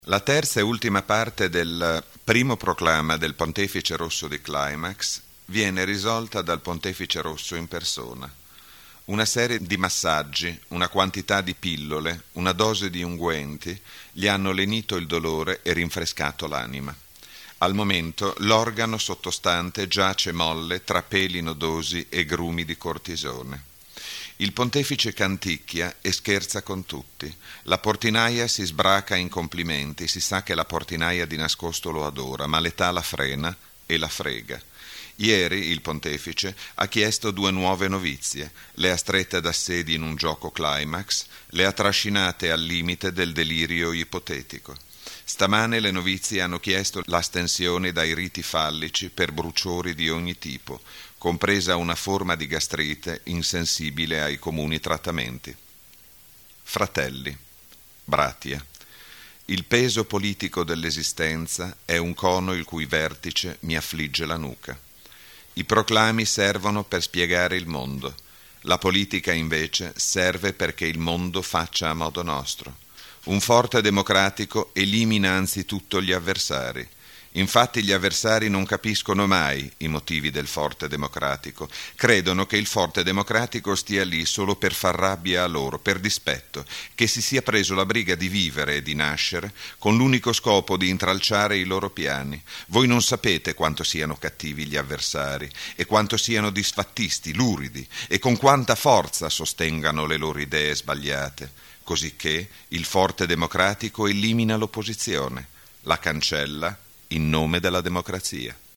Letture